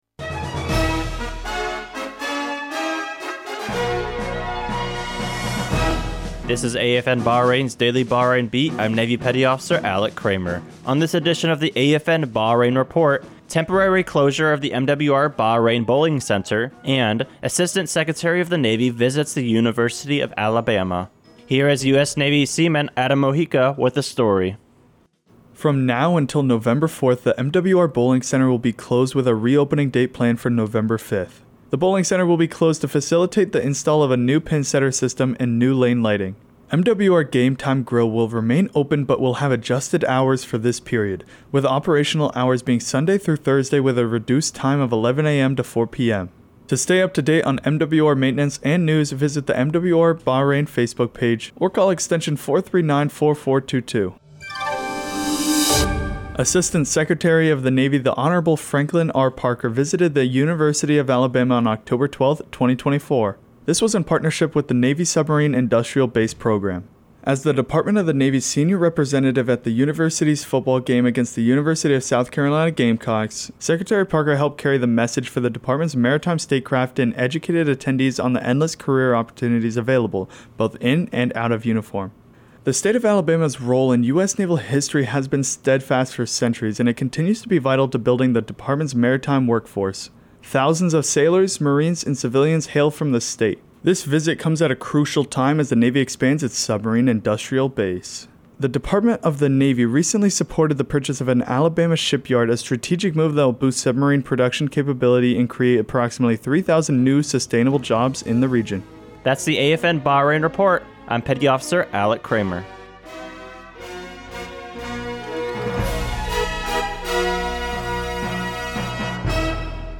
newscastsBahrain BeatAFN Bahrain